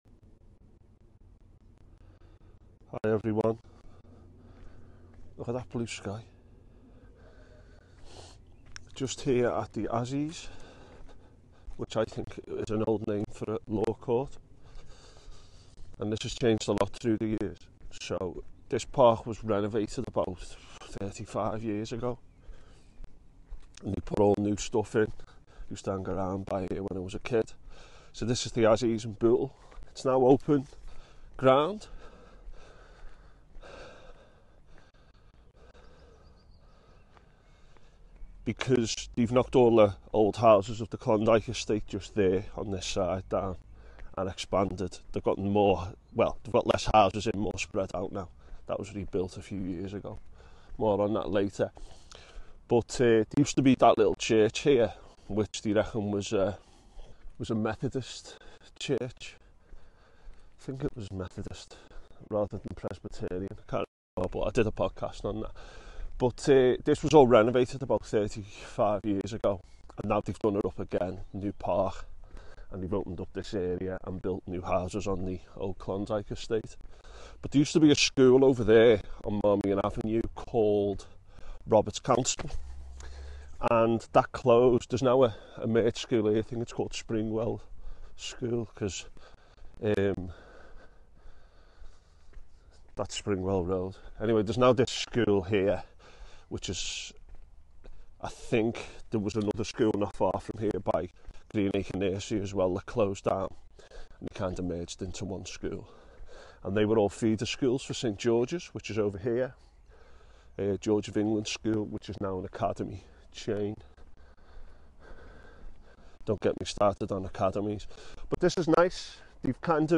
Today I take a walk through the Azzies (which I now know is named after Aspinall’s Field) and into Bootle Cemetery. I speak to a member of the public, visit the Blitz and War Graves, and point out other key parts and sections.